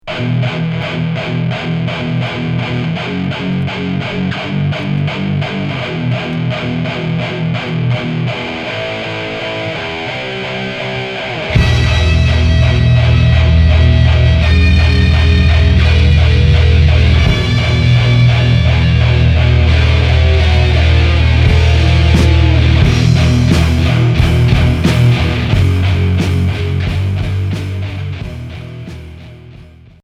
Punk core